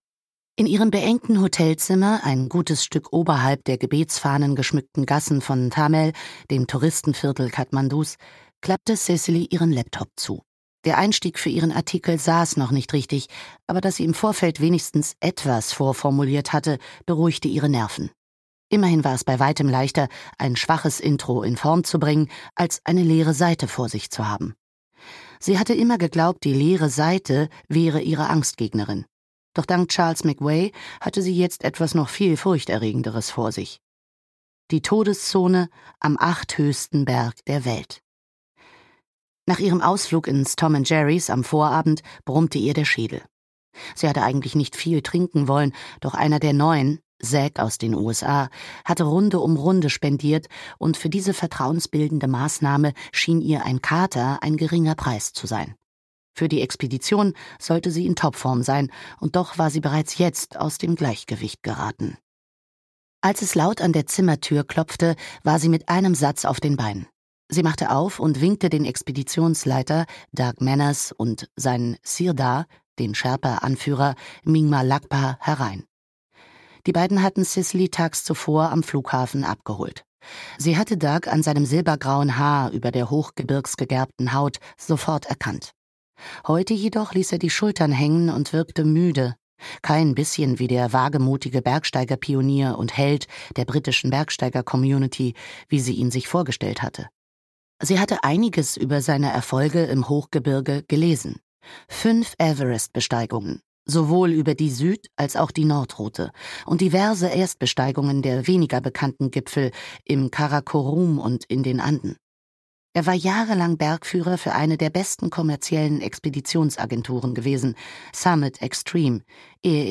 Audio knihaDer Aufstieg – In eisiger Höhe wartet der Tod (DE)
Ukázka z knihy